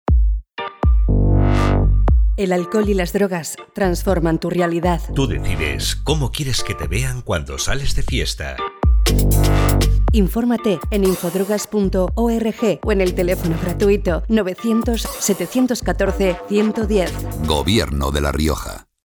Elementos de Campaña Cuña radiofónica Cuña 20".